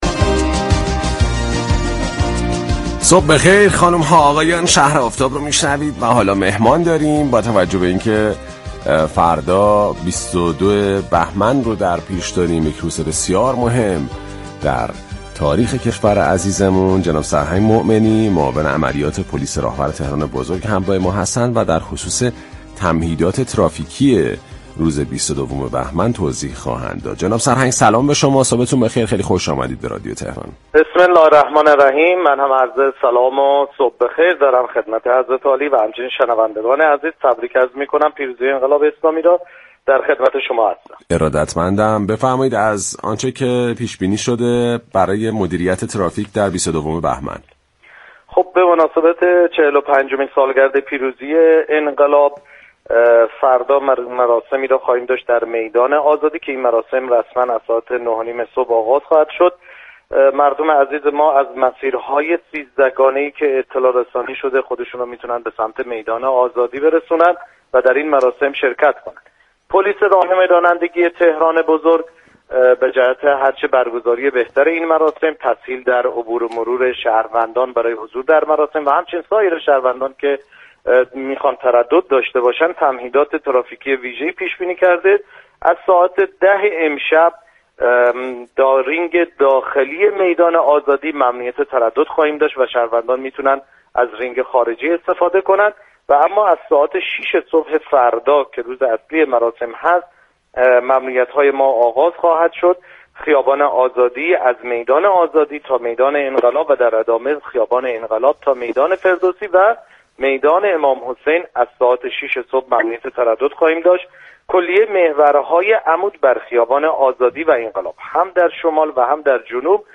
به گزارش پایگاه اطلاع رسانی رادیو تهران، سرهنگ احسان مومنی معاون عملیات پلیس تهران بزرگ در گفت و گو با «شهر آفتاب» اظهار داشت: به مناسبت چهل و پنجمین سالگرد پیروزی انقلاب اسلامی ایران، راهپیمایی روز 22 بهمن از ساعت 0900 صبح آغاز و شهروندان تهرانی پس از طی مسیرهای دوازده گانه تعیین شده، در میدان بزرگ آزادی جمع می‌شوند.